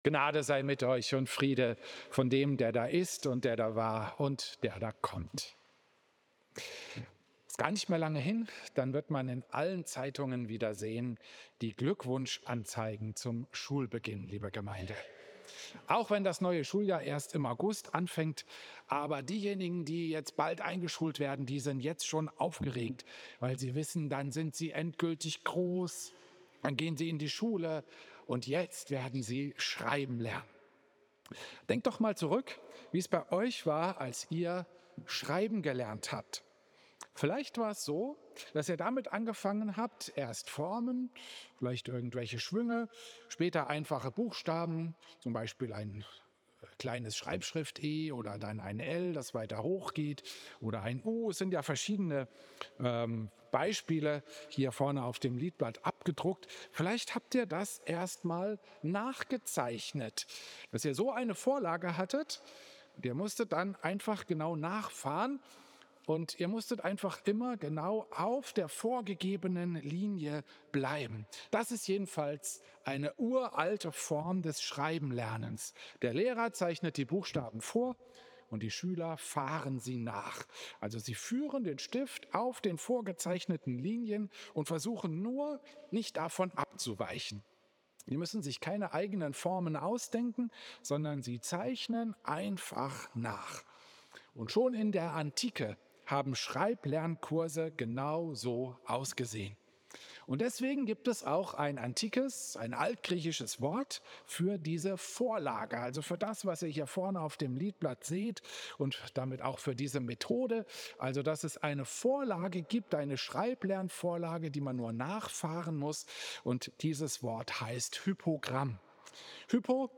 Klosterkirche Volkenroda, 19. April 2026
Predigten